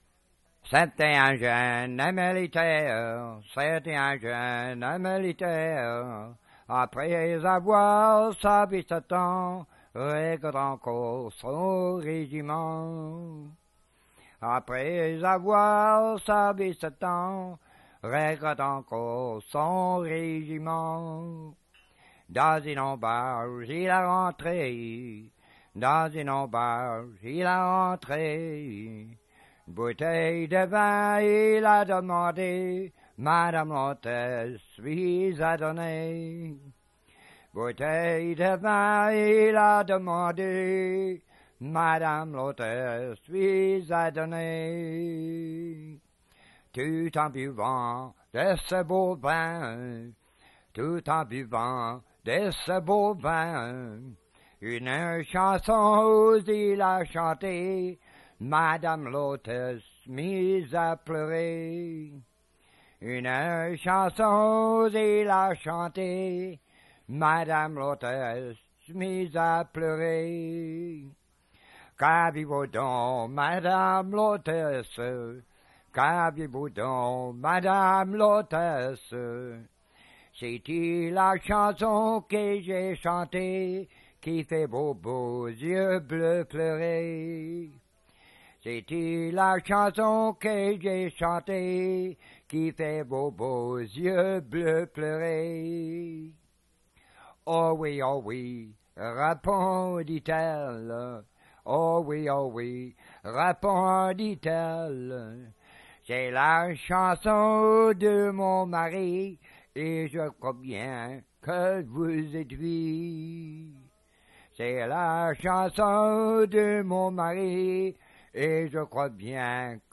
Emplacement L'Anse-aux-Canards